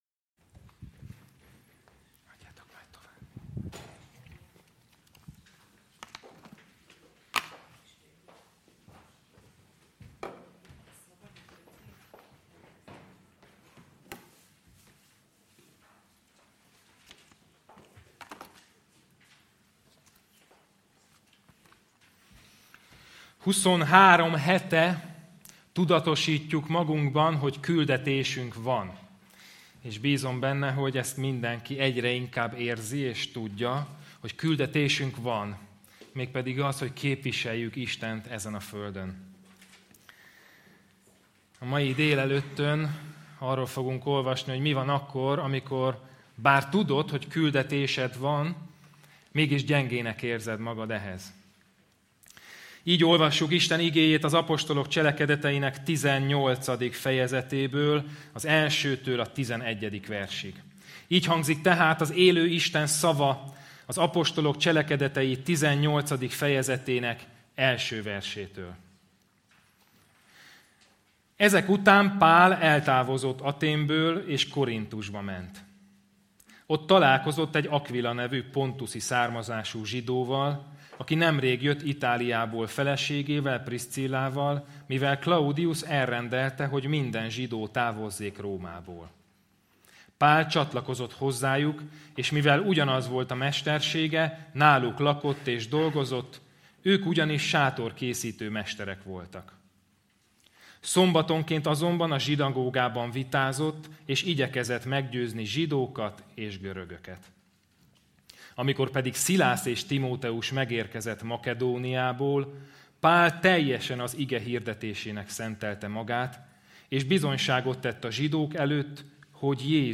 Küldetésünk van Passage: ApCsel 18,1-11 Service Type: Igehirdetés Bible Text